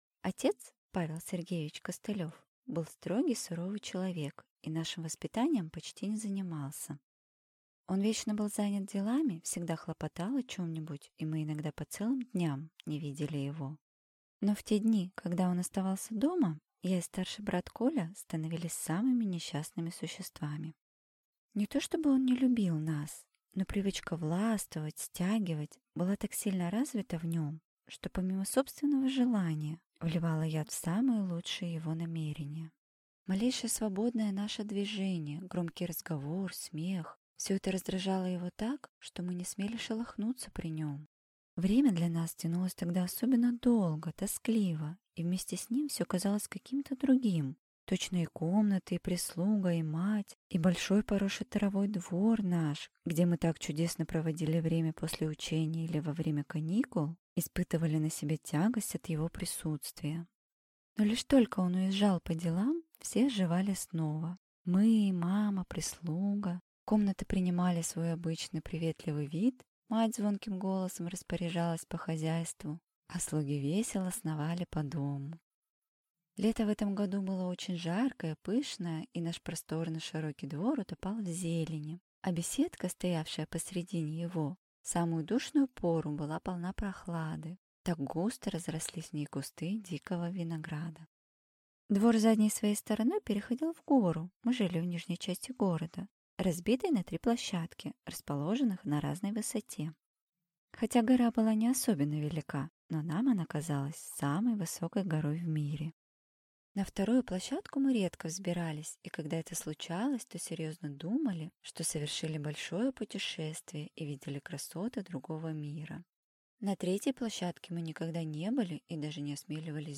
Аудиокнига Гора | Библиотека аудиокниг